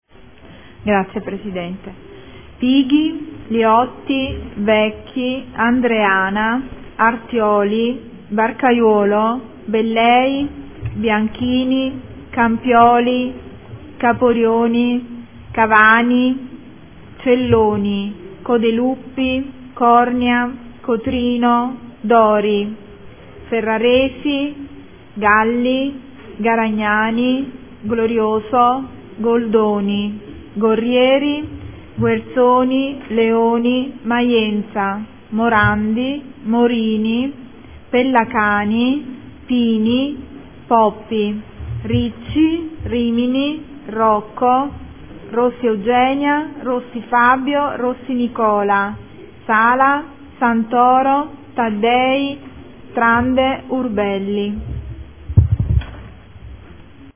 Segretario — Sito Audio Consiglio Comunale
Seduta del 20/03/2014. Appello.